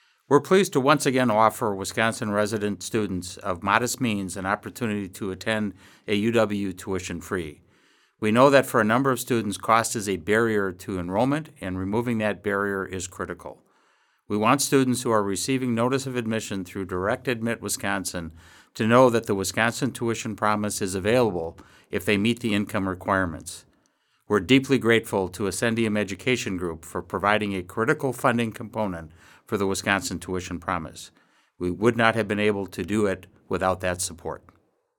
Listen: President Rothman
audio message about Wisconsin Tuition Promise